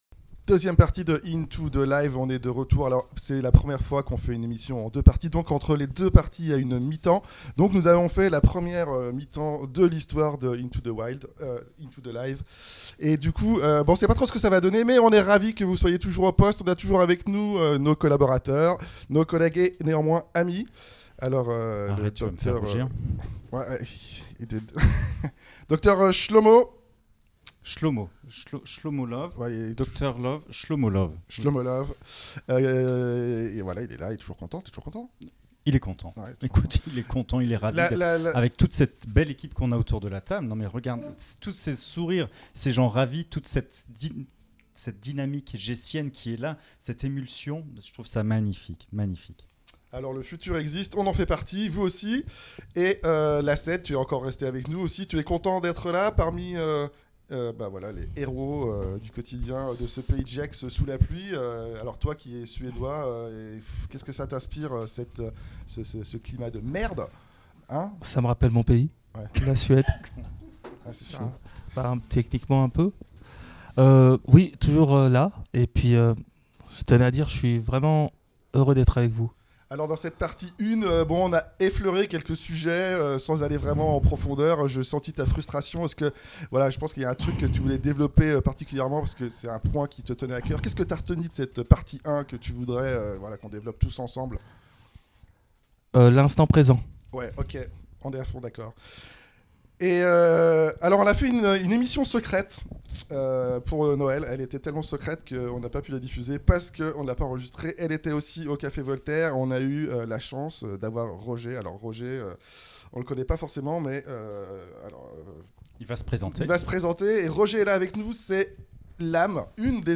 Deuxième mi-temps décisive ou l'on découvre sous accompagnement acoustique l'influence des légumes verts dans la propagation des idées dissidentes, des radios pirates, du Zadisme à Voltaire et de l'érotisme encore un peu!